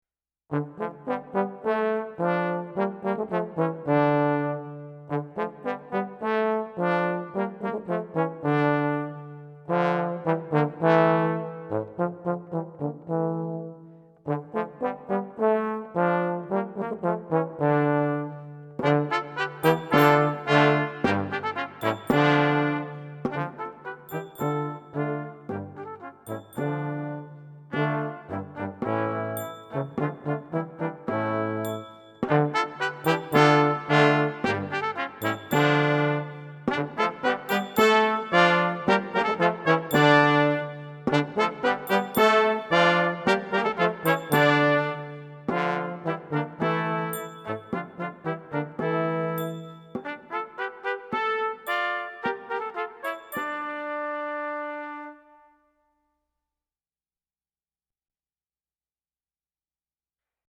Composer: French Carol
Voicing: Brass Quartet